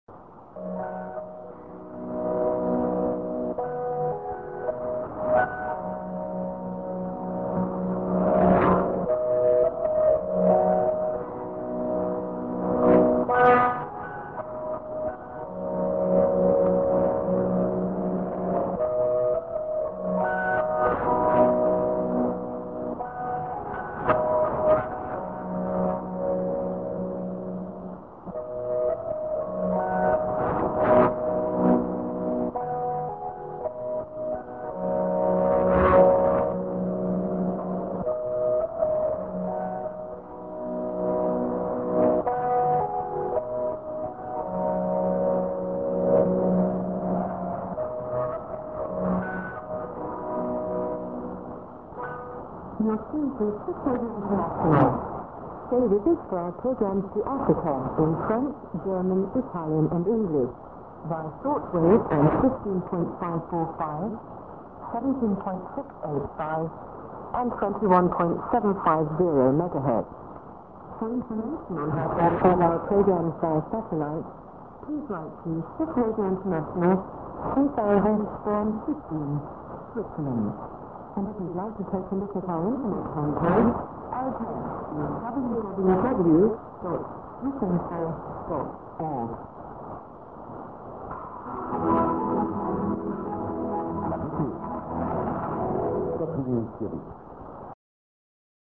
St. IS->00'50":ID+SKJ(man) New Freq.